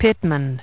Transcription and pronunciation of the word "pitman" in British and American variants.